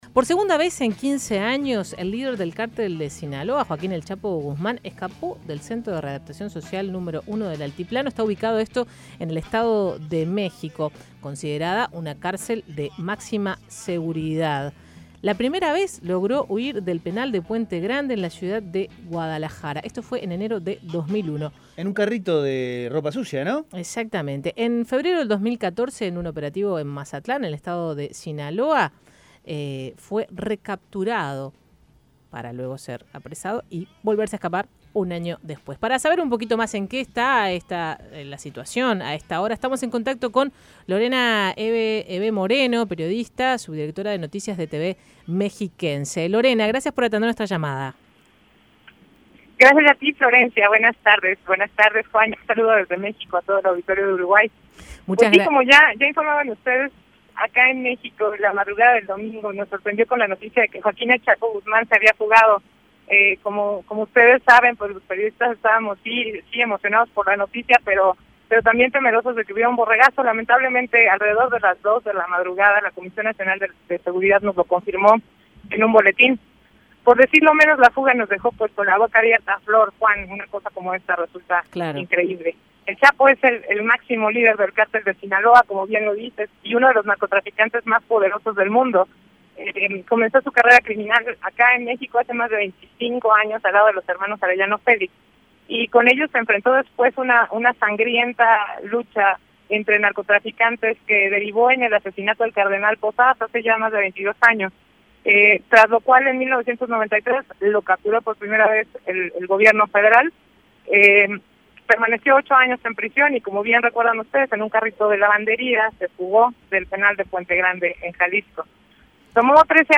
Escuche la entrevista a la periodista